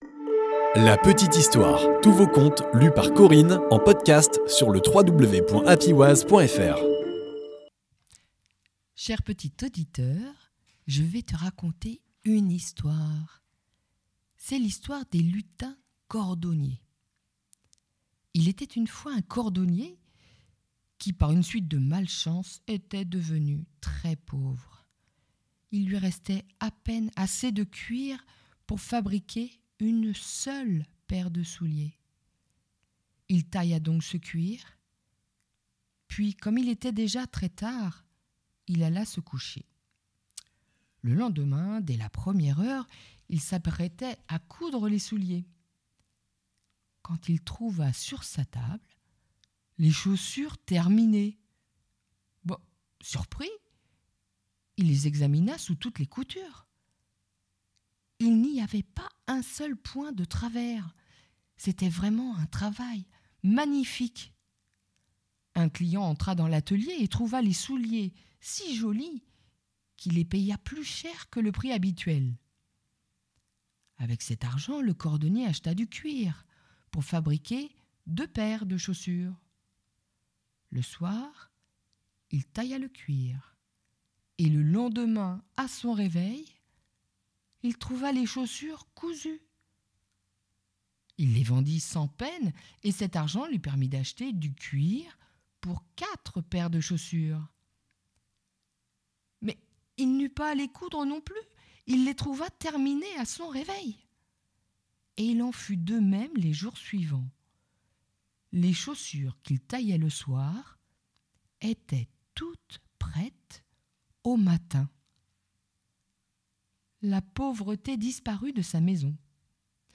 conte
Narratrice